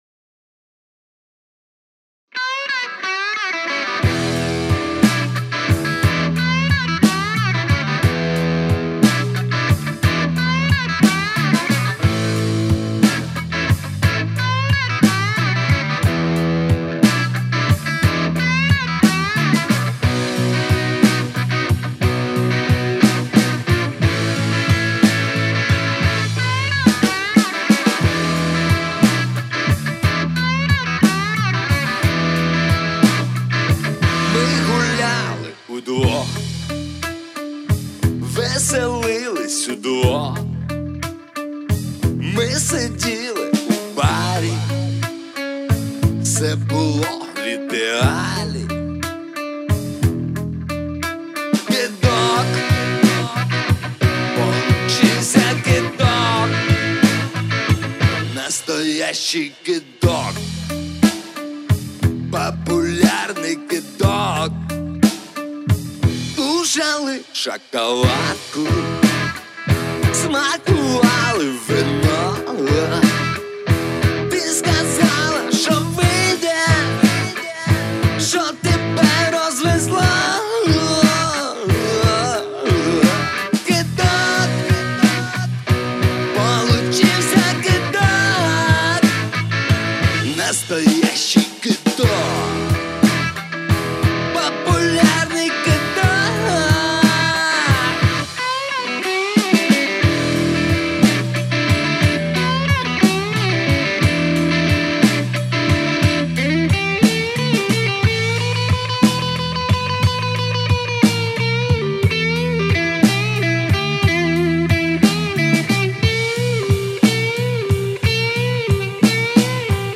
Blues-Rock, конструктивня криика, сведение.
Записали трек в стиле блюз. В группе три человека.